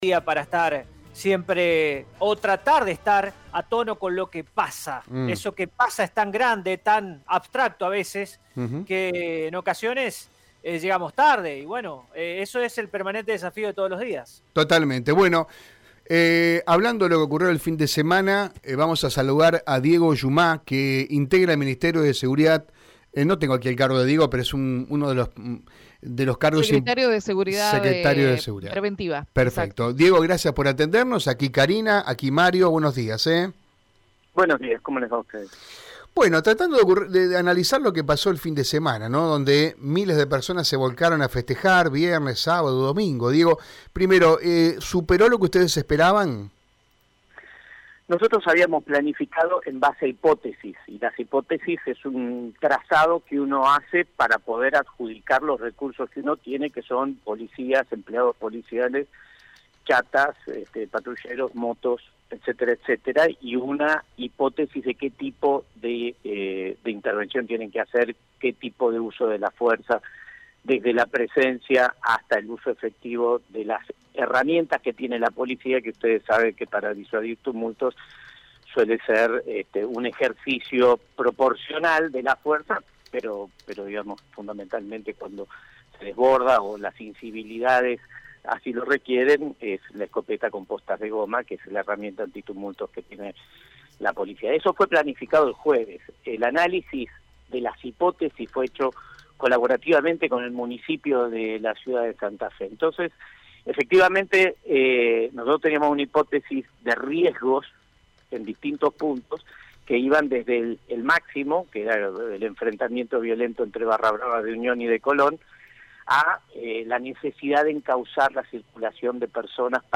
En diálogo con Radio EME, el subsecretario de Seguridad Preventiva, Diego Lluma, se refirió a los resultados que dejaron los operativos y la reacción desmedida de las personas.